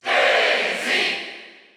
Daisy_Cheer_German_SSBU.ogg